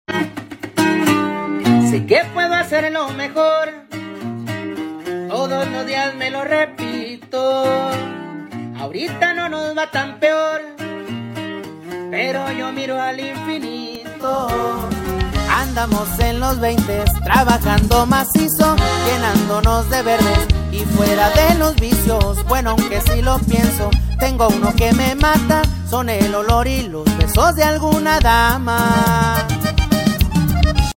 6.7 2015 Ford Diesel #13 Sound Effects Free Download